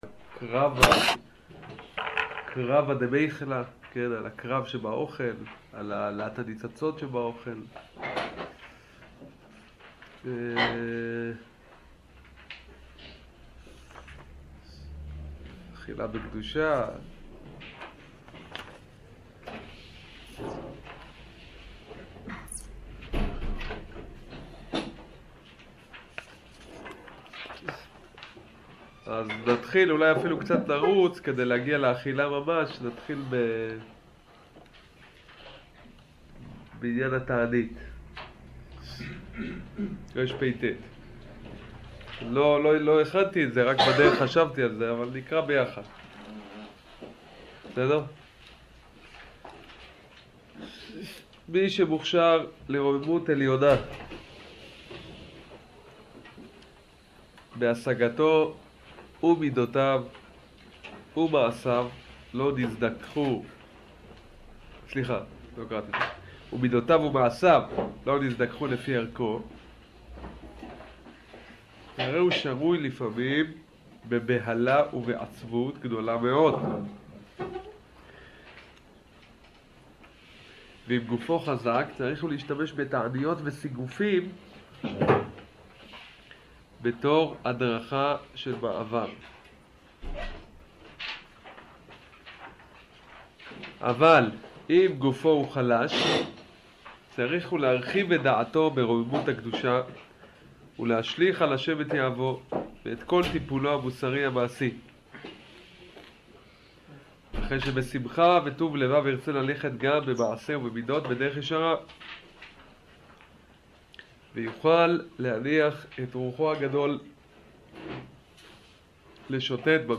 שיעור פסקאות כה